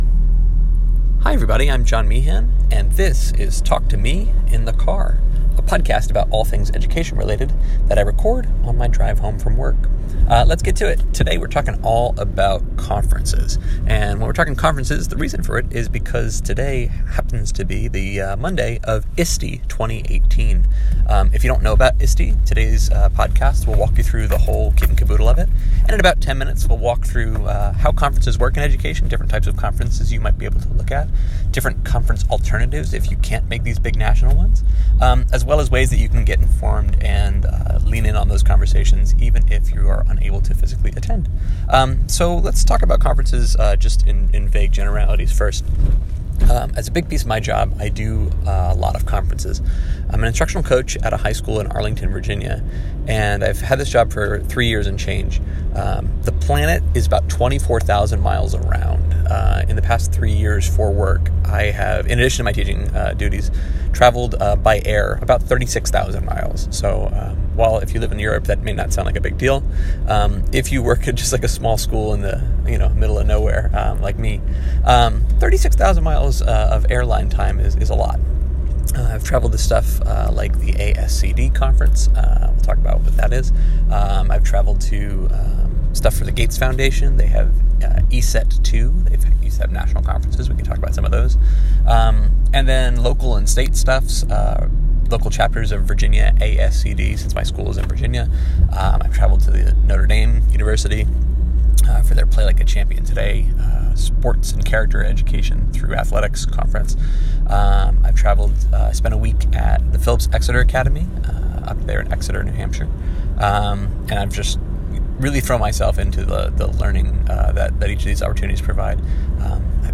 And that’s why finding a quality teaching conference can be a total game-changer for folks looking to level up their professional practice. Recorded in bumper-to-bumper …